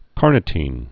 (kärnĭ-tēn)